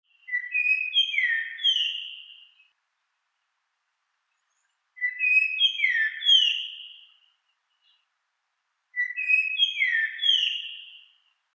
イカル｜日本の鳥百科｜サントリーの愛鳥活動
「日本の鳥百科」イカルの紹介です（鳴き声あり）。体は灰色で翼、尾、それに頭上は青味のある黒色です。